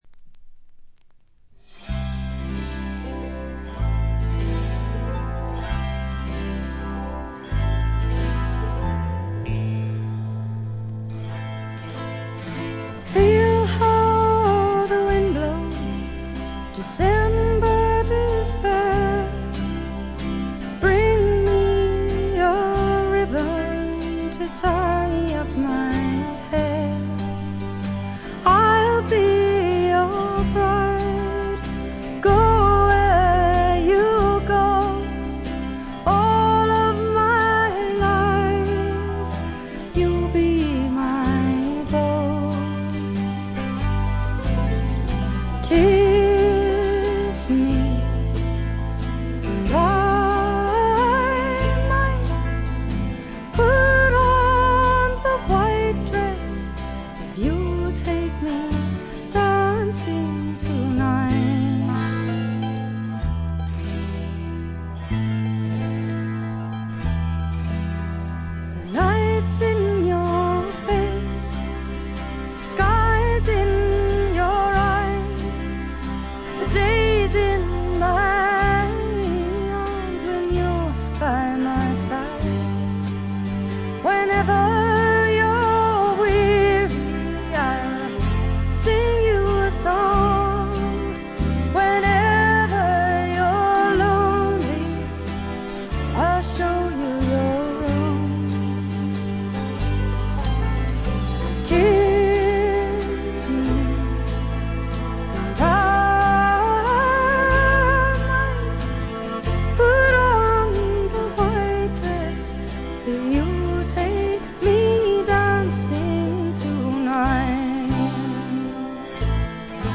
チリプチ多めですが、大きなノイズはありません。
試聴曲は現品からの取り込み音源です。
Recorded and mixed at Olympic Sound Studios, London